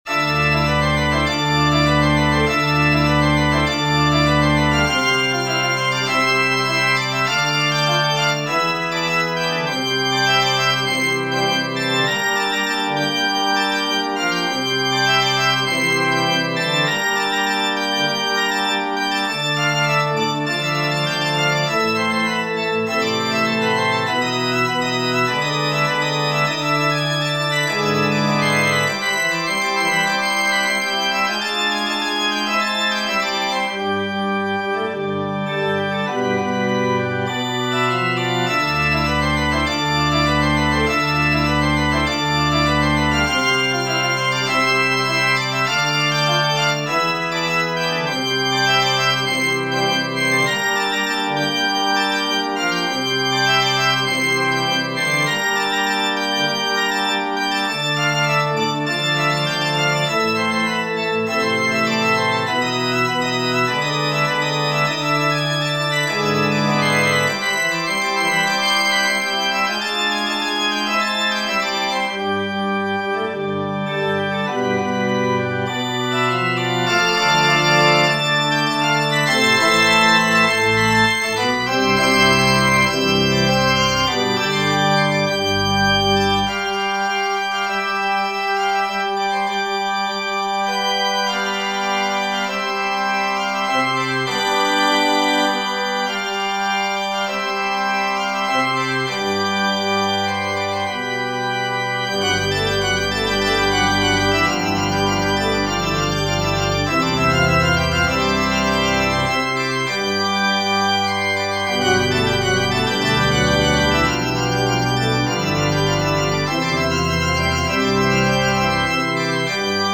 Organ Music